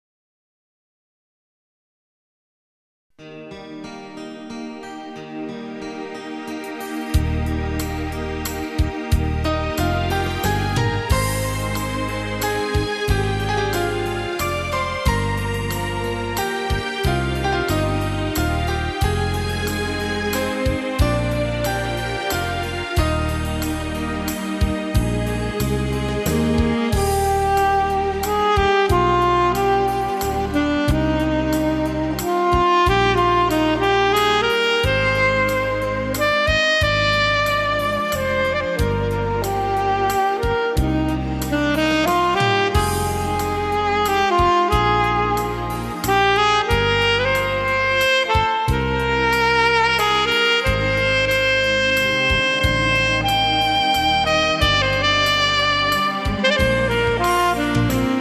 Valzer lento
12 brani per sax e orchestra.